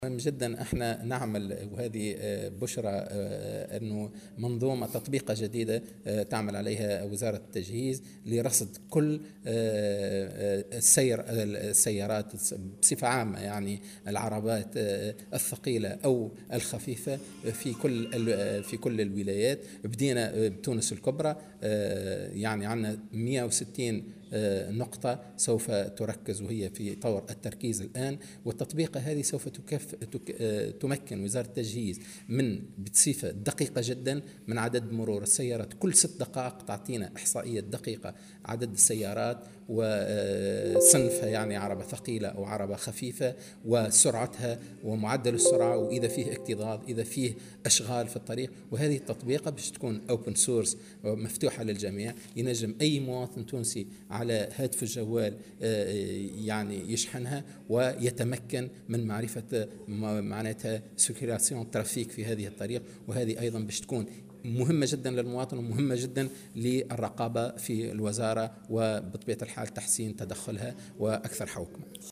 وأضاف خلال جلسة استماع له، اليوم الاثنين، بلجنة الحوكمة الرشيدة ومكافحة الفساد، أنه تم الانطلاق في تركيز هذه التطبيقة بالنسبة لتونس الكبرى وعلى مستوى 160 نقطة، مشيرا إلى أن هذه التقنية ستساعد على تقديم إحصائيات دقيقة بخصوص عدد السيارات وسرعتها و صنفها.